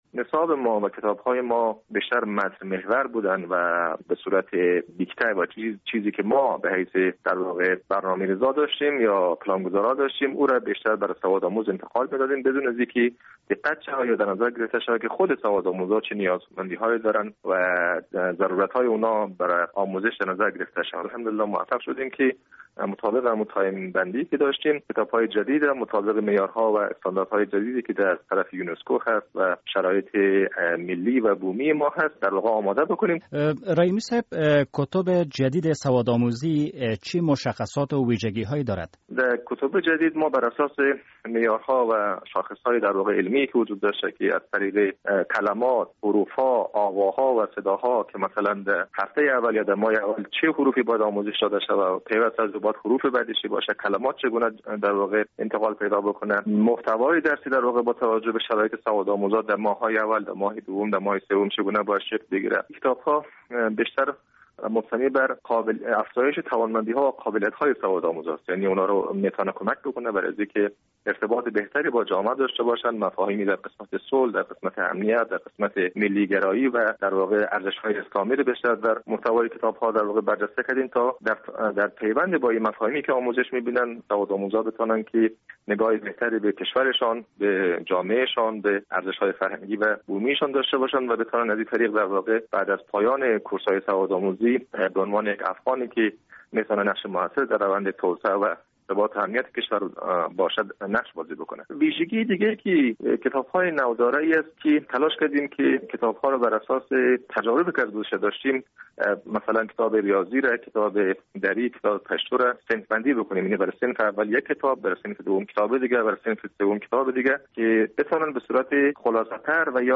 مصاحبه - صدا